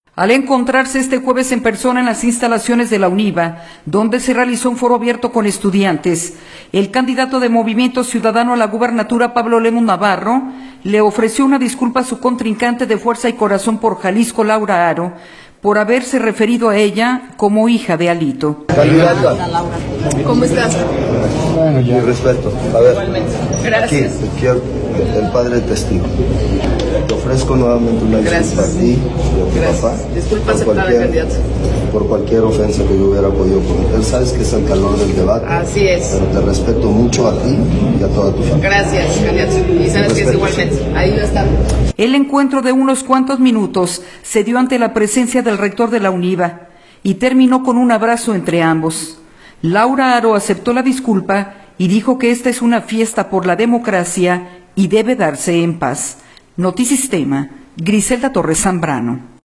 audio Al encontrarse este jueves en persona en las instalaciones de la UNIVA, donde se realizó un foro abierto con estudiantes, el candidato de Movimiento Ciudadano a la gubernatura, Pablo Lemus Navarro, le ofreció una disculpa a su contrincante de Fuerza y Corazón por Jalisco, Laura Haro, por haberse referido a ella como “hija de Alito”.